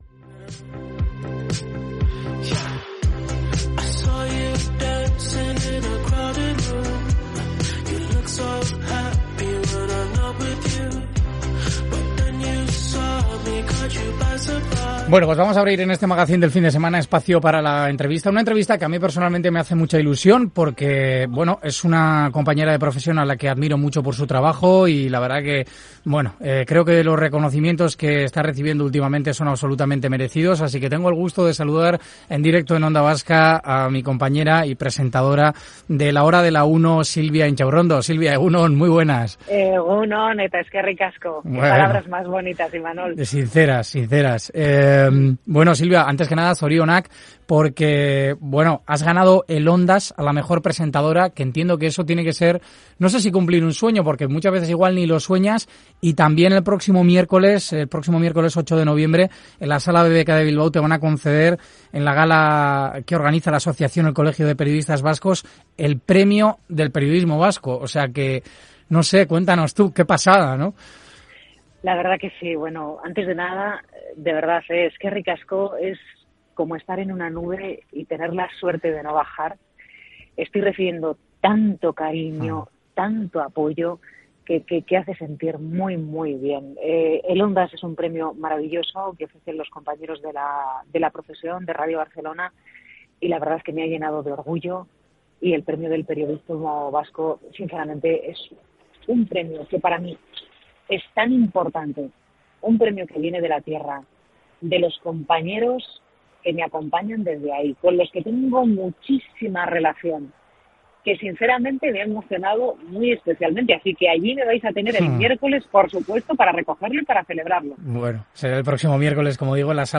Hoy se ha pasado por Onda Vasca para hablarnos de todo lo que han supuesto para ella estos reconocimientos, permitiéndonos descubrir cómo es Silvia Intxaurrondo cuando se apagan los focos del plató.